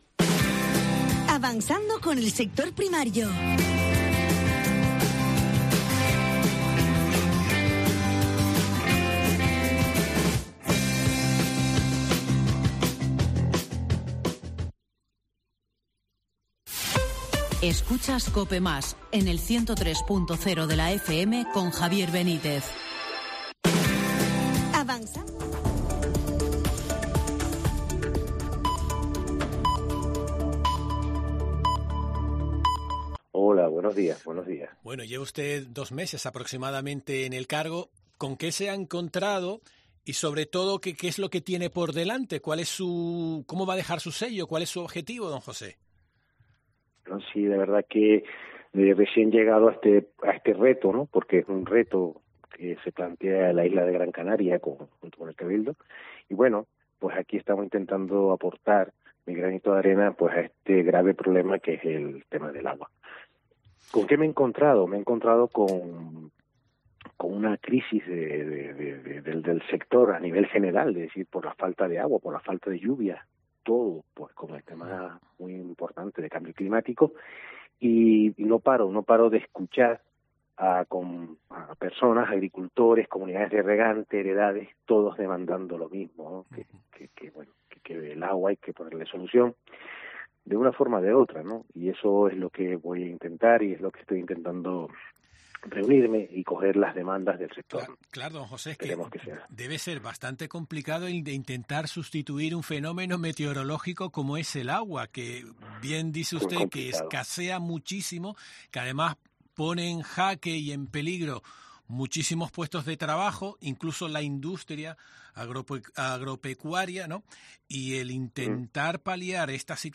Entrevista José Juan Pérez, director insular de Aguas de la consejería del Sector Primario